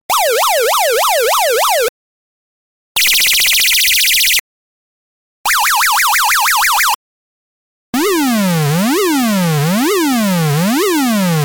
「９５６１基板」の音色をスイッチで変更して、１６通りのサウンドを発生することのできる基板です。
上記サンプル音は下記Ｎｏ．１２〜Ｎｏ．１５の順番で再生されます。
警察の音
雀の鳴き声
鳥の鳴き声
レーシングカー通過音